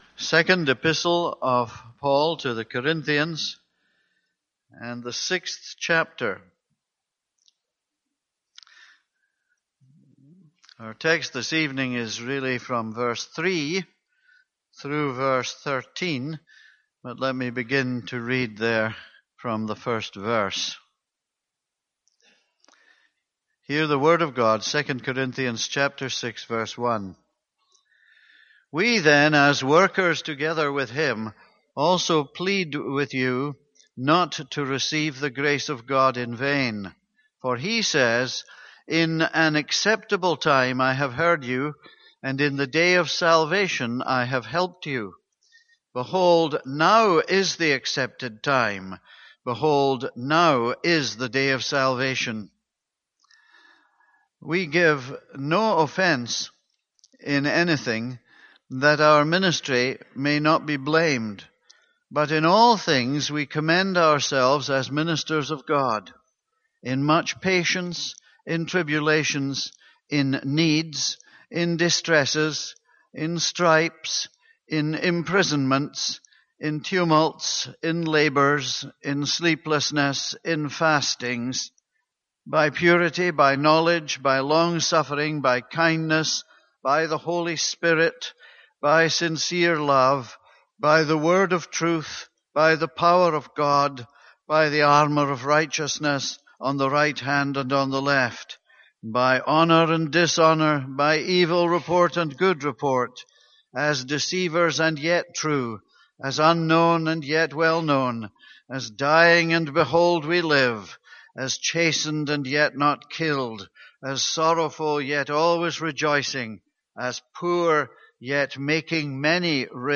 This is a sermon on 2 Corinthians 6:3-13.